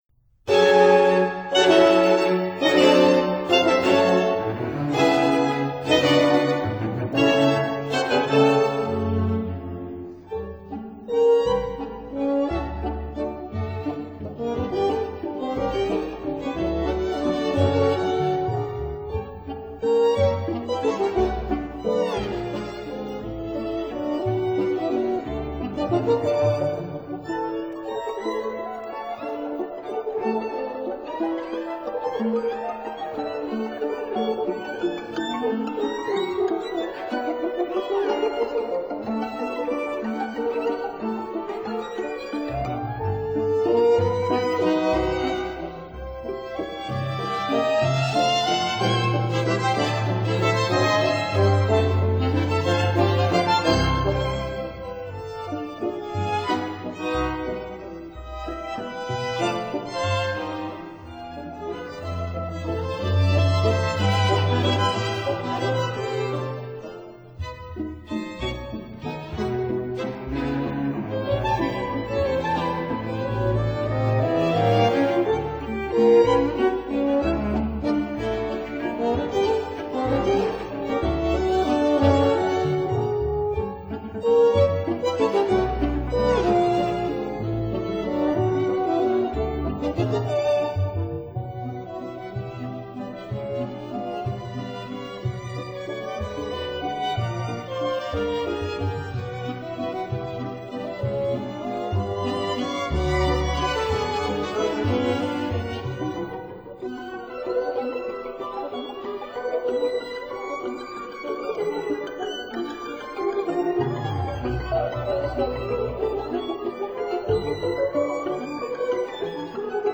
for bassoon & string quintet
violin
viola
cello
double bass
oboe
bassoon
horn
piano